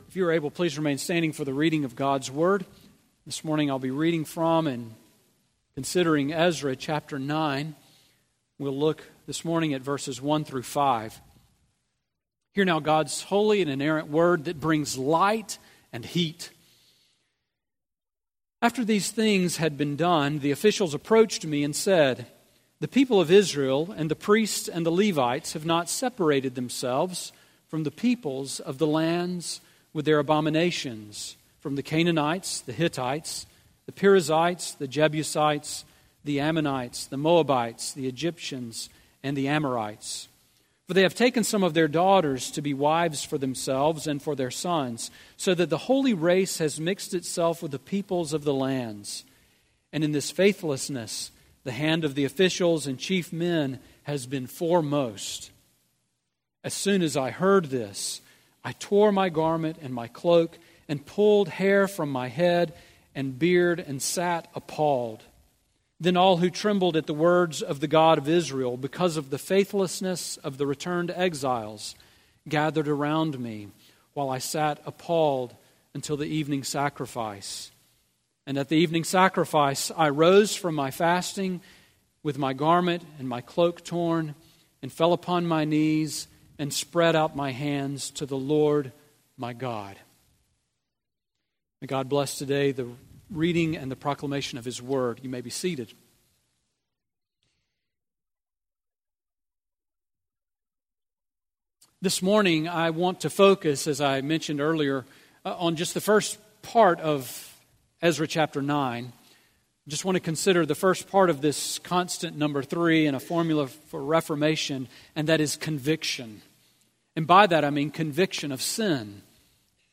Sermon on EZRA 9:1-5 from August 6, 2017